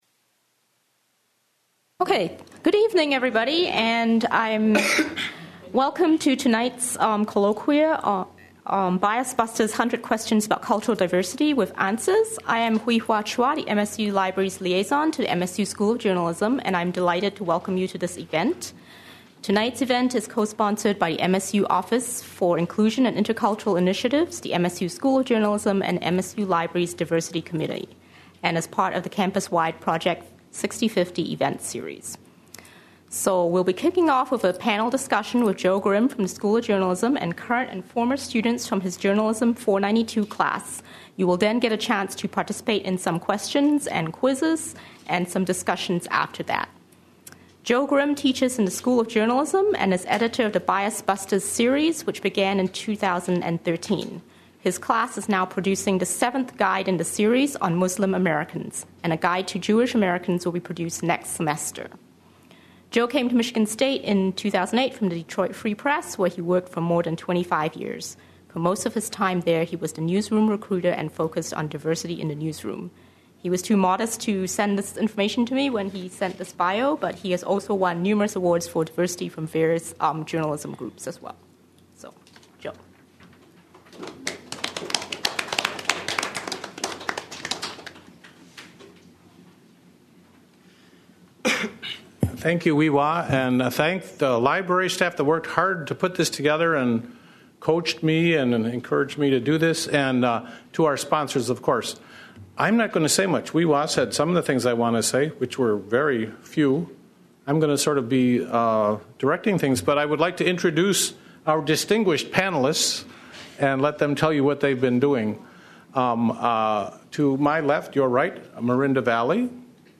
Panelists take questions from the audience. A breakout discussion session is not recorded.
Held in the Main Library.